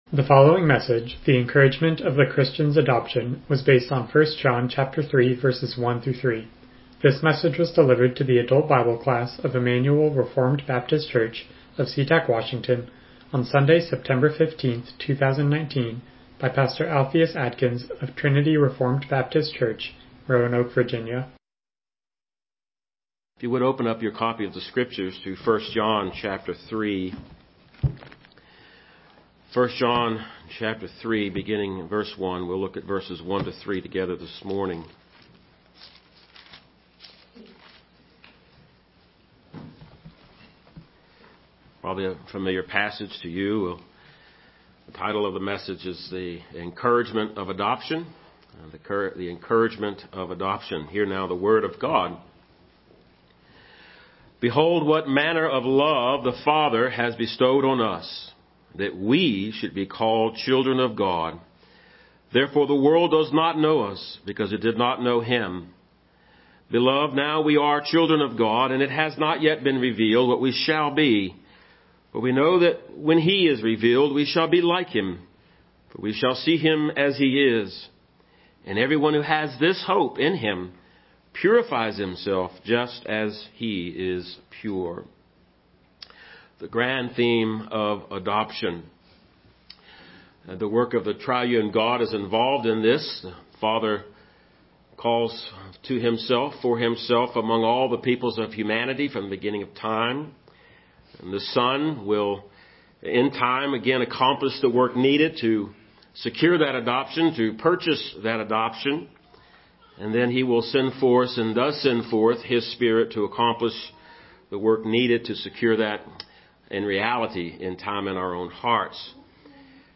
Passage: 1 John 3:1-3 Service Type: Sunday School